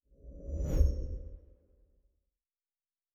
Distant Ship Pass By 5_1.wav